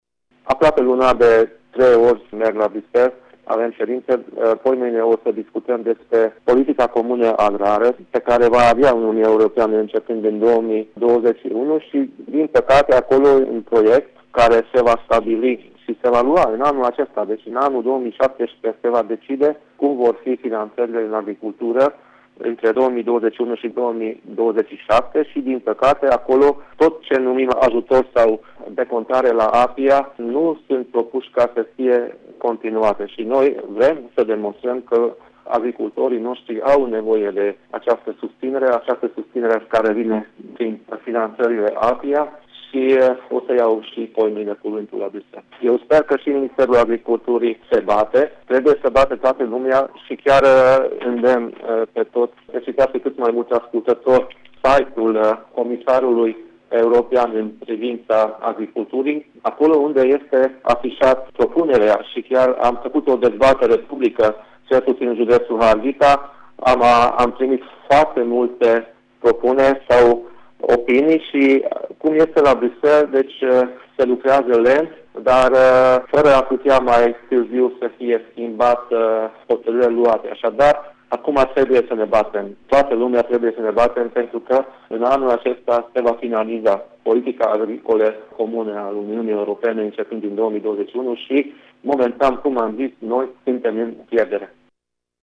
Din proiect lipsesc ajutoarele și decontările care acum se fac prin intermediul APIA, spune președintele Consiliului Județean Harghita Borboly Csaba, care este totodată membru al Comitetul Regiunilor: